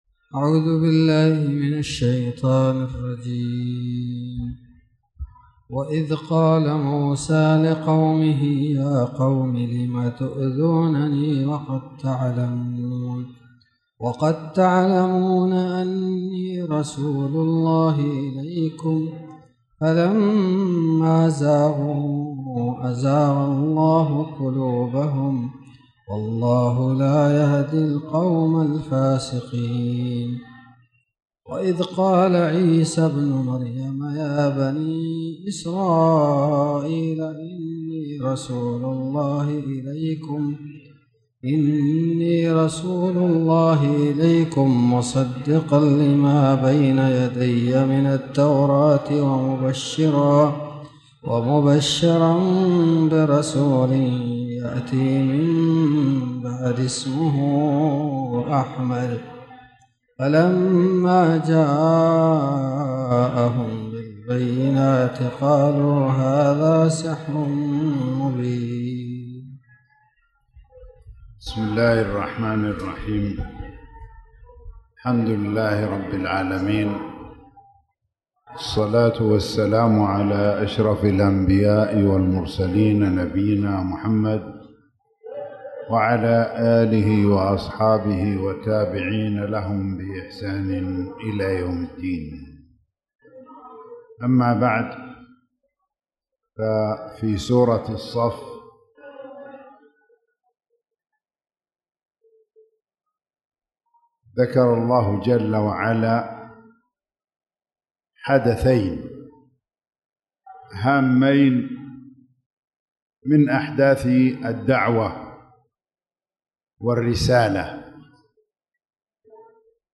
تاريخ النشر ١٠ ربيع الثاني ١٤٣٨ هـ المكان: المسجد الحرام الشيخ